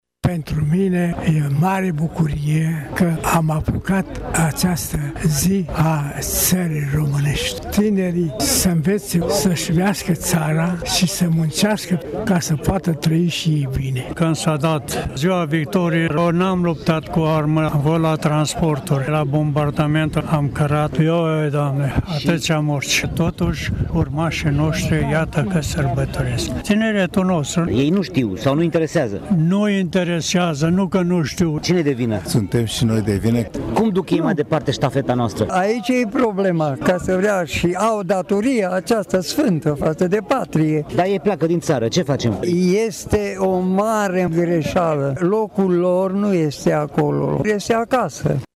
Veteranii de război prezenți la ceremonie au atras atenția că noile generații sunt datoare să nu uite cele întâmplate: